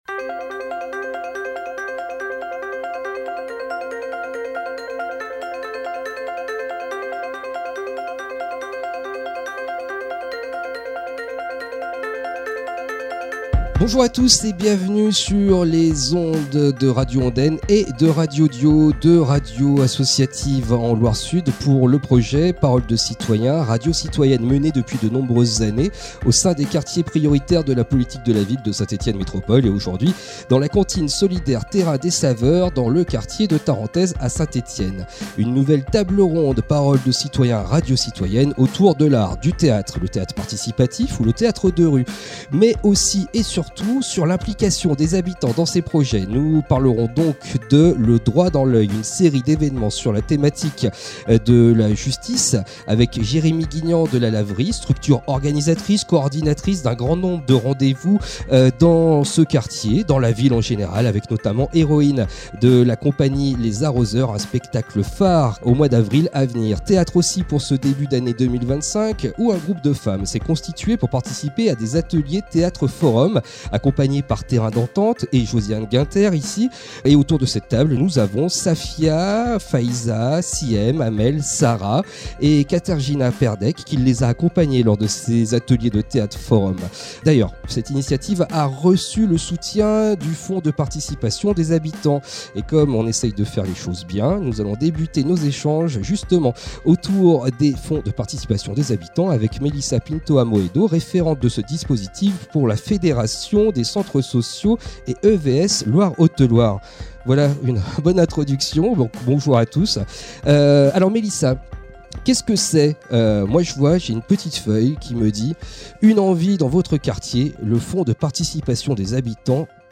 Une nouvelle table ronde Paroles de Citoyens, Radio Citoyennes autour de l’art, du théâtre, participatif ou de rue, mais aussi et surtout, autour l’implication des habitants dans des projets.
Table ronde enregistrée le mercredi 26 Février, dans les locaux de Terrains des Saveurs, 47 rue de Tarentaize à Saint-Etienne.